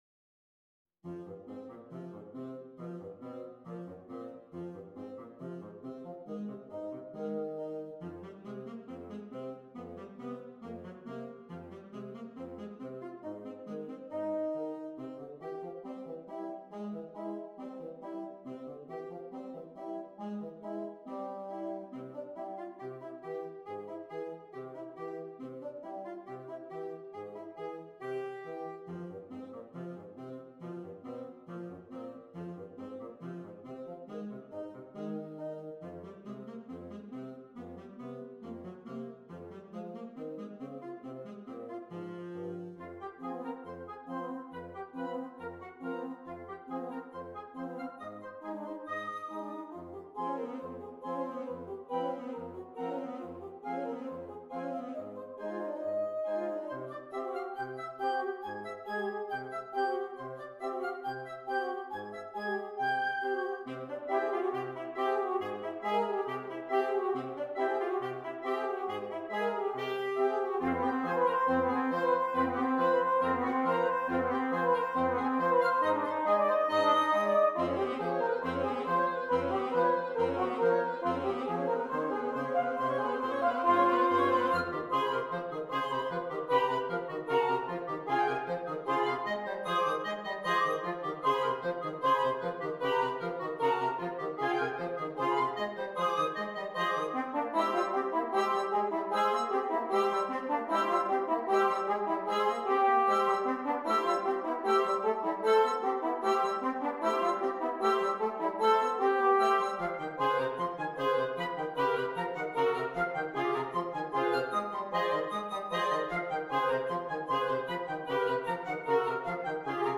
Interchangeable Woodwind Ensemble
PART 1 - Flute, Oboe, Clarinet
PART 5 - Baritone Saxophone, Bass Clarinet, Bassoon